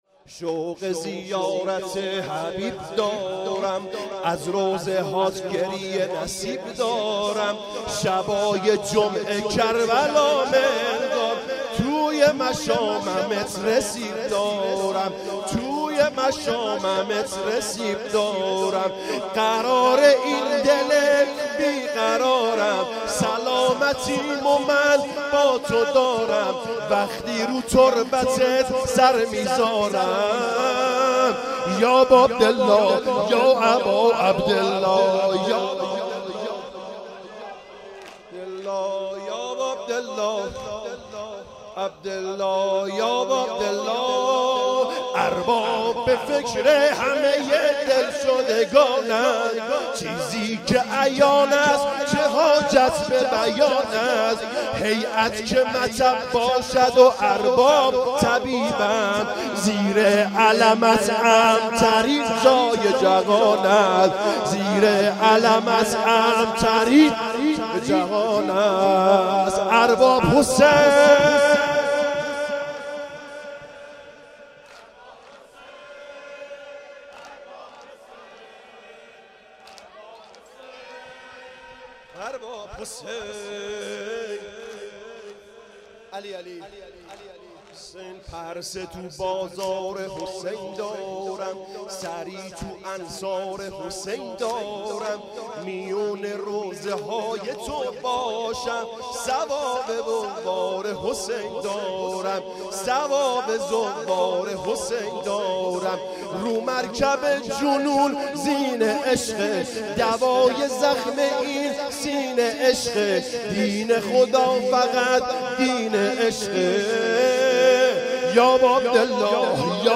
شب ششم محرم
حسینیه کربلا